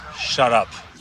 One of the most iconic Arnold Schwarzenegger quotes.